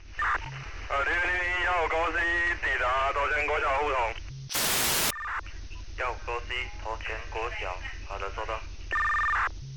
Mdc1200_taiwan.mp3